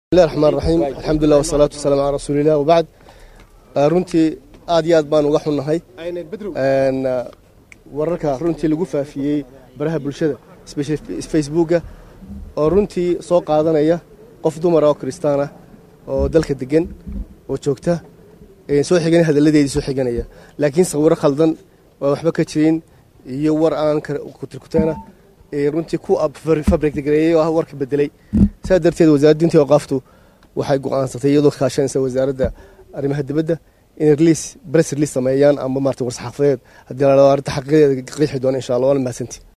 Dhagayso Codka: Maamulka Soomaaliland oo ka hadlay wararka la xariira Kaniisad laga furayo Magaalada Hargeysa | Goobsan Media Inc
Wasiirka Diinta iyo Awqaafta maamulka Somaliland Sheekh Khaliil Cabdilaahi Axmed oo Warbaahinta kula hadlayay Magaalada Hargeysa ayaa sheegay in Warkaas intiisa badan ay ku arkeen Baraha ay bulsahda isticmaasho islamarkaana ay baari doonaan dhawaana ay War-Saxaafadeed ka soo saari doonan.
codka-wasiirka-diinta-iyo-awqaafta.mp3